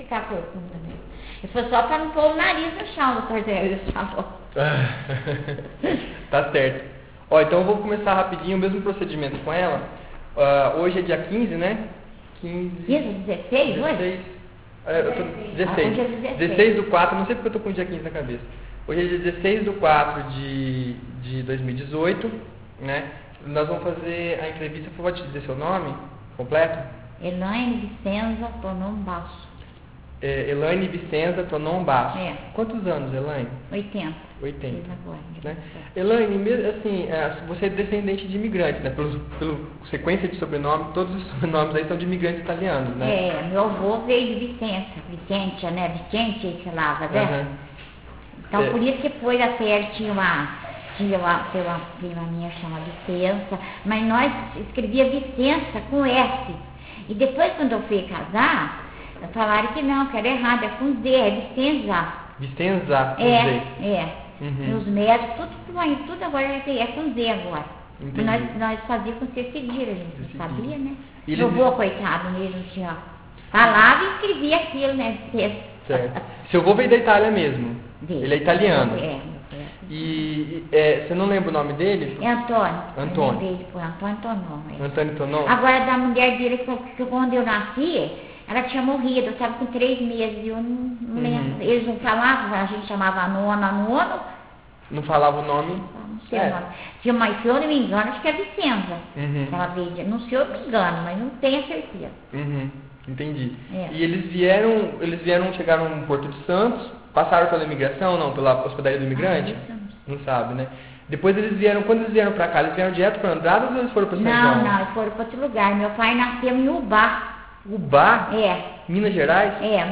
[Entrevista].